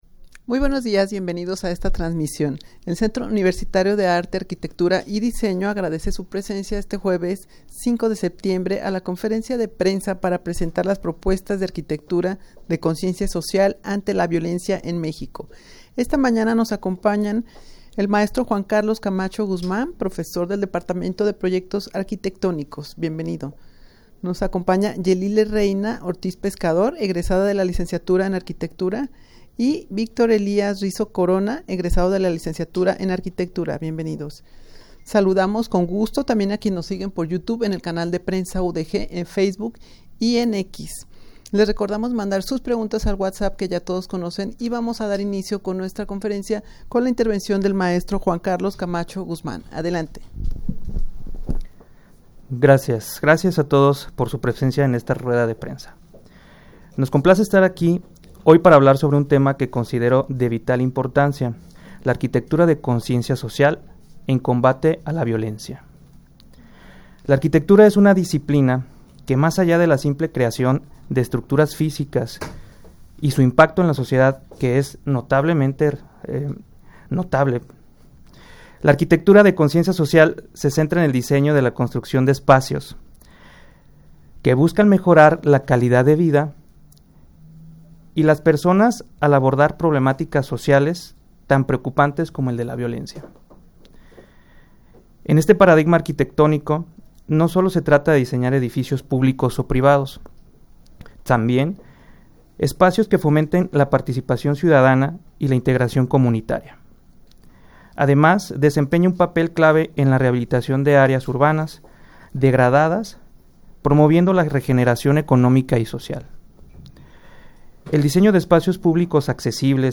Audio de la Rueda de Prensa
rueda-de-prensa-para-presentar-propuestas-de-arquitectura-de-conciencia-social-ante-la-violencia-en-mexico.mp3